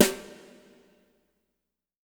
BW BRUSH02-L.wav